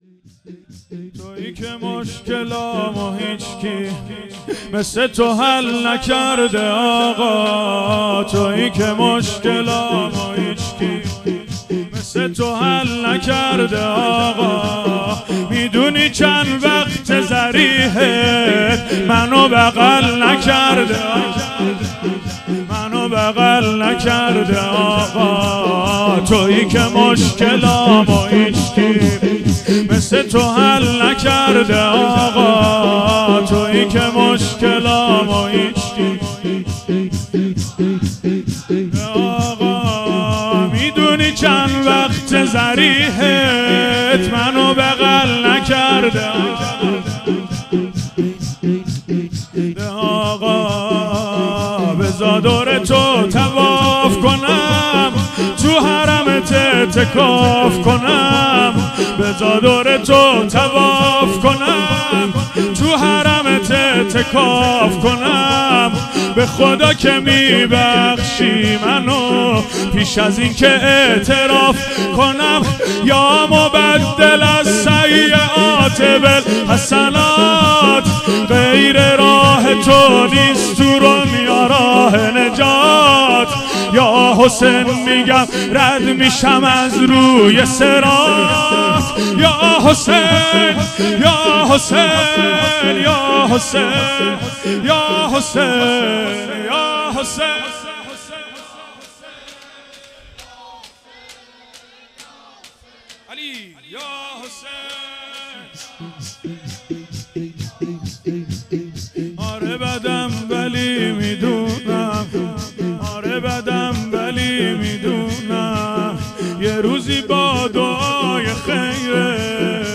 شور | تویی که مشکلامو هیچکی
دهه اول محرم الحرام ۱۴۴۴